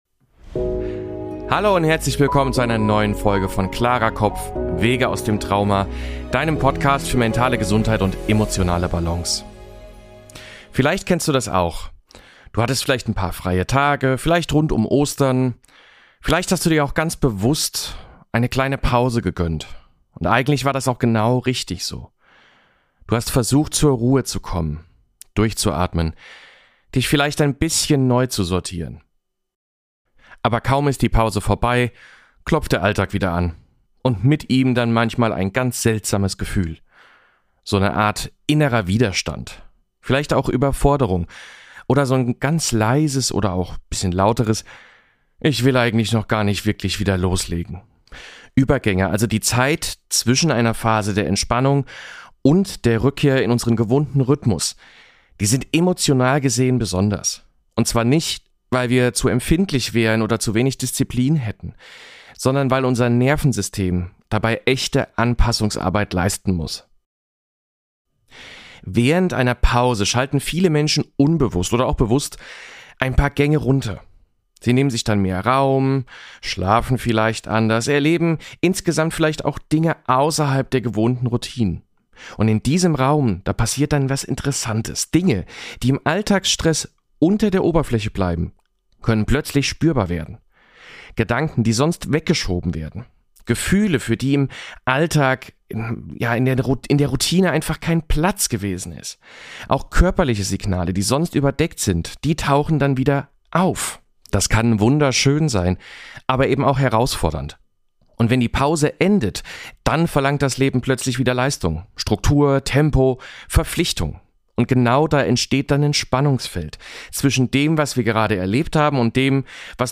In dieser Folge erfährst du, warum Übergänge nach einer Pause so schwierig sein können, welche emotionalen Blockaden dabei typisch sind – und wie du mit EFT (Emotional Freedom Techniques) sanft und selbstwirksam wieder in deinen Flow kommst. Inklusive geführter EFT-Runde zum Mitmachen!